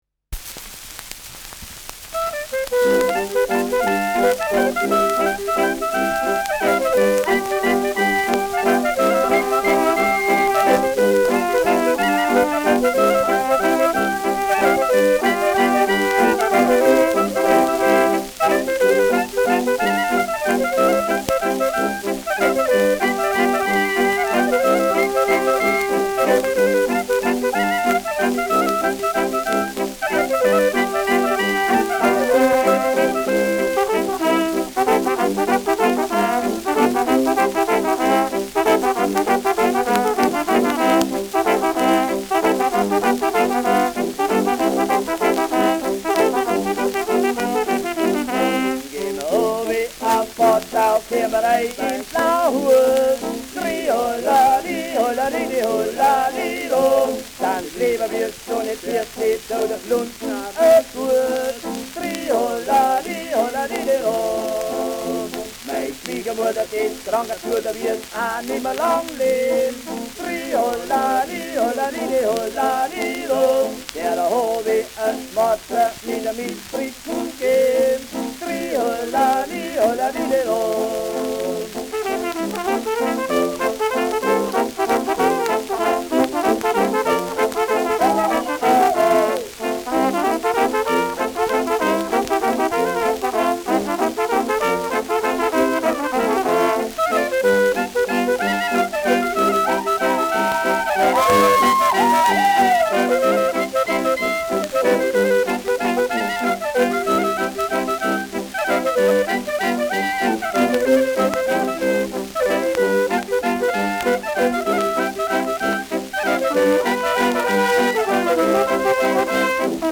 Gehn ma obi af Passau : Walzer mit Gesang [Gehen wir hinunter nach Passau : Walzer mit Gesang]
Schellackplatte
leichtes Rauschen : leichtes Knistern : leichtes Leiern : vereinzeltes Knacken